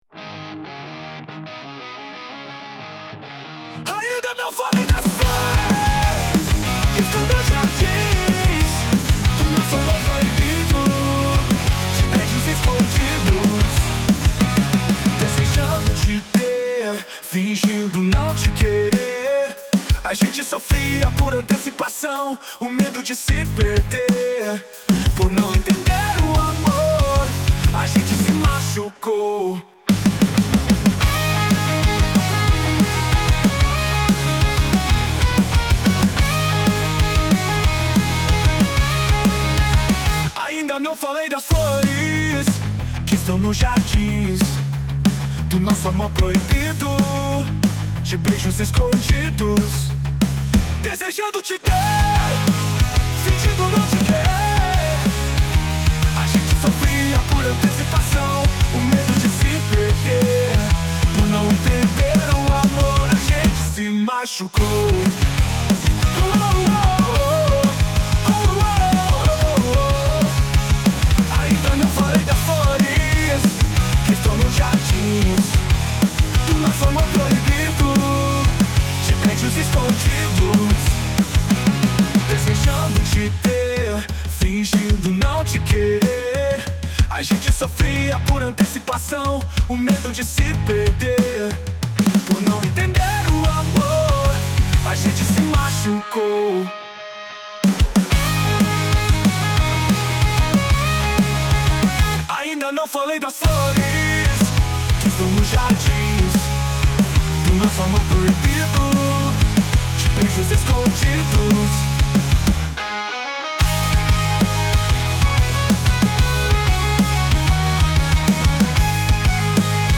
EstiloGrunge